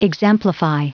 Prononciation du mot exemplify en anglais (fichier audio)
Prononciation du mot : exemplify
exemplify.wav